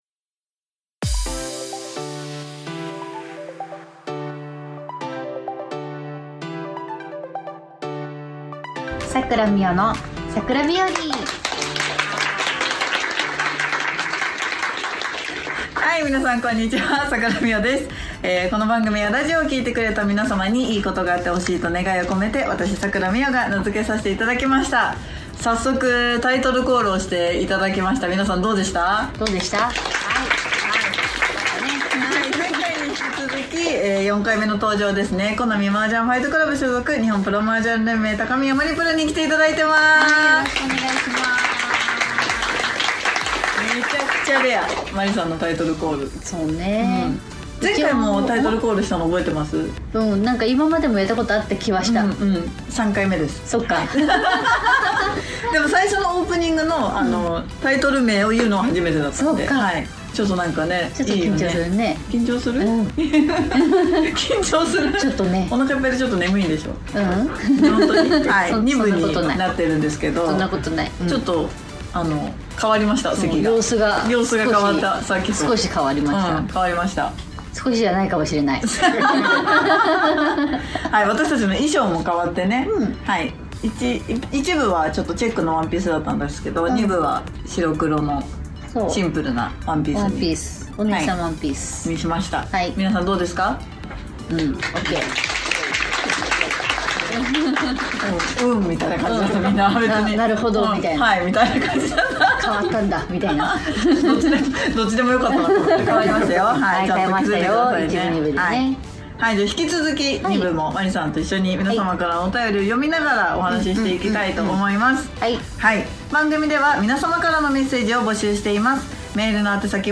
公開収録第二部も高宮まりさんとのトークです♪おふたりは対局後のルーティーンは何があるのでしょうか・・！？